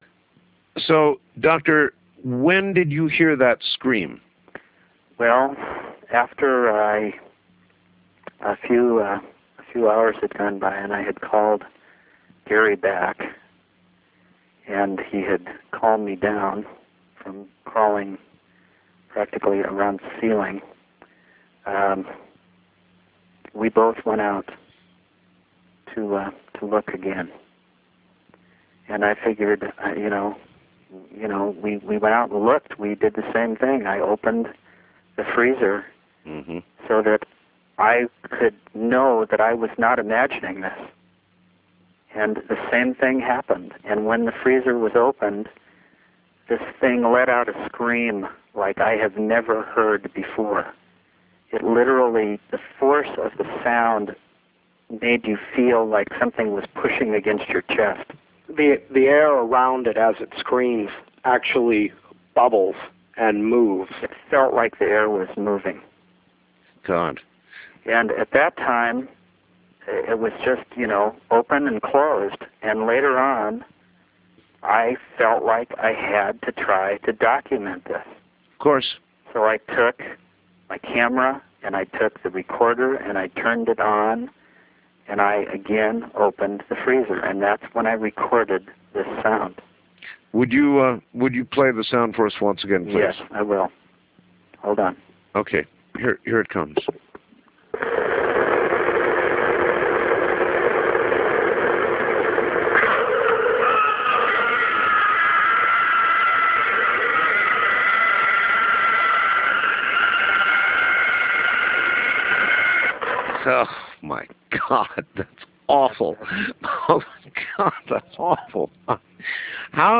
The creature let out a blood curtling scream which filled the air with terror, among other things...